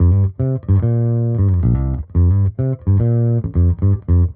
Index of /musicradar/dusty-funk-samples/Bass/110bpm
DF_JaBass_110-C.wav